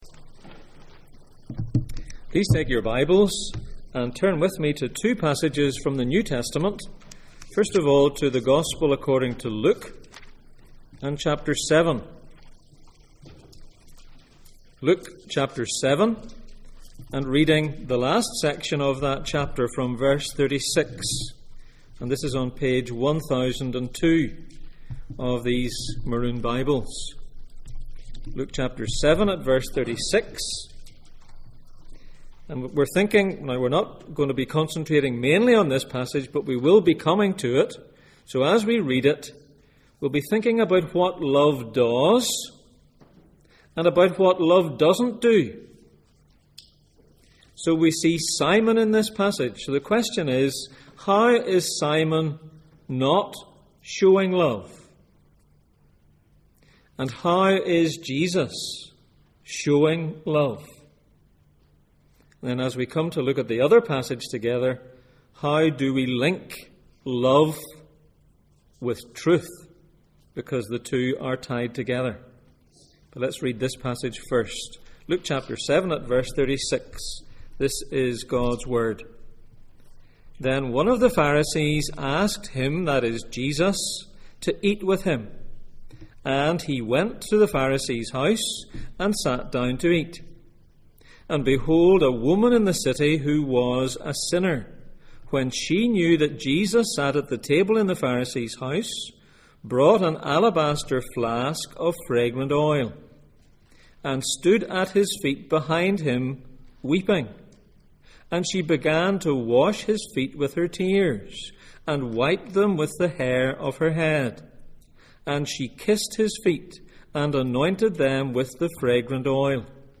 In praise of love Passage: Luke 7:36-50, 1 Corinthians 13:4-7 Service Type: Sunday Morning